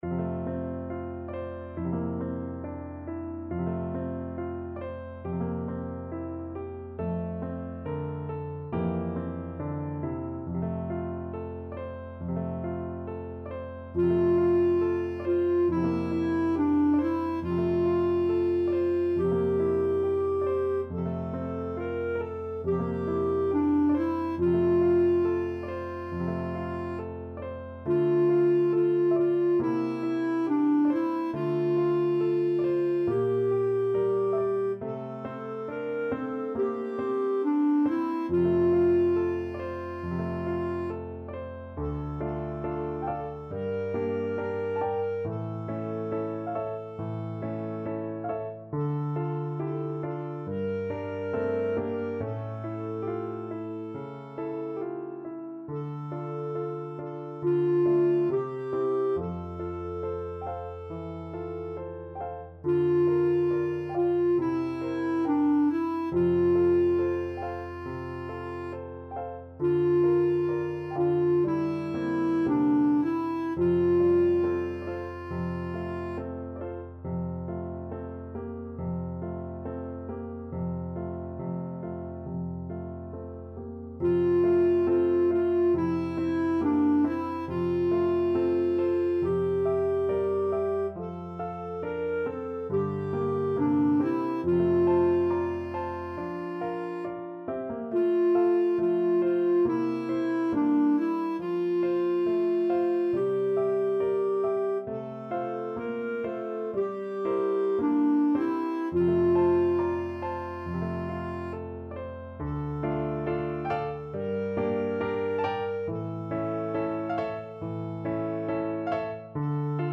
Clarinet
Traditional Music of unknown author.
F major (Sounding Pitch) G major (Clarinet in Bb) (View more F major Music for Clarinet )
4/4 (View more 4/4 Music)
~ = 69 Andante tranquillo
Classical (View more Classical Clarinet Music)